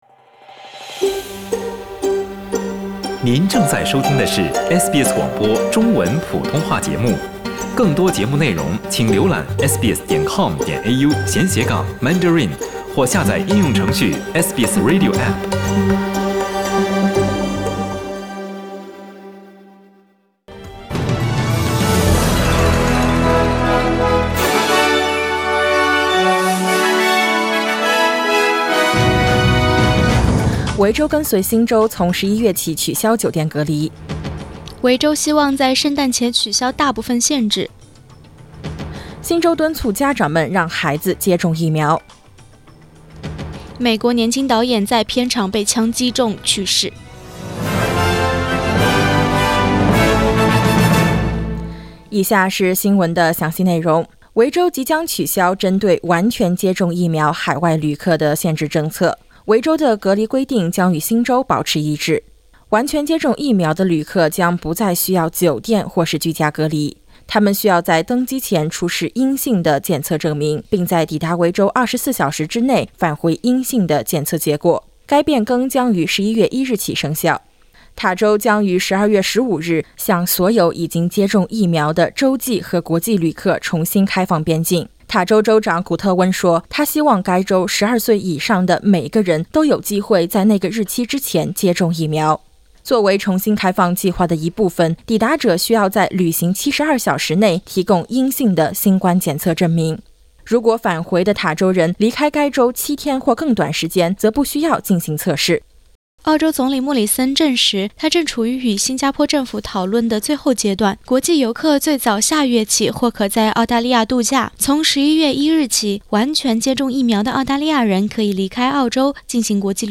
SBS Mandarin morning news Source: Getty Images